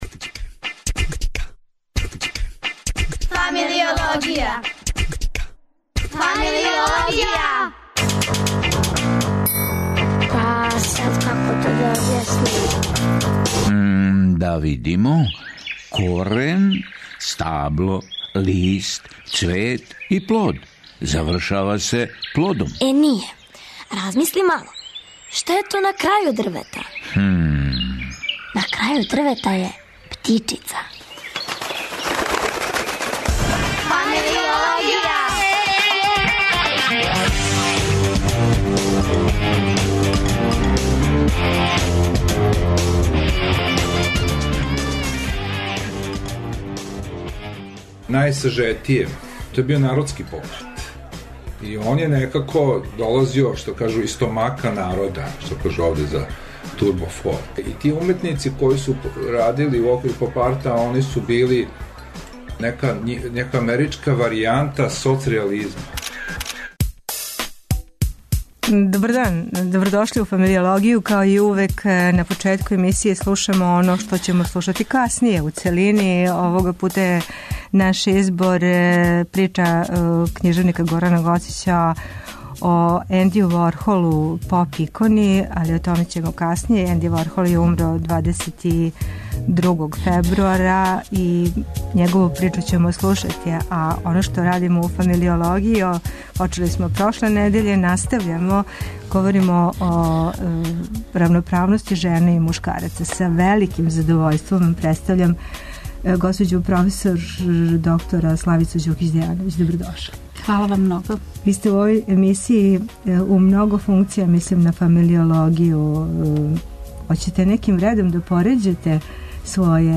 Гошћа у студију је проф. др Славица Ђукић Дејановић, неуропсихијатар и директор психијатријске установе "Др Лаза Лазаревић".